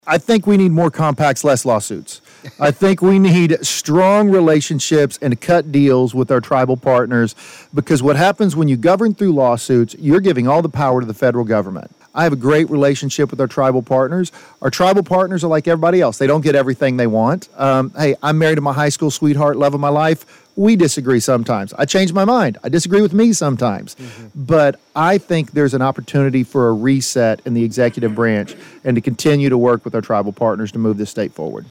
Former Oklahoma House Majority Floor Leader Jon Echols was our guest Friday morning on KWON's Community Connection, where he discussed his bid to become the next Oklahoma Attorney General.
Jon Echols on Tribal Relations 6-20.mp3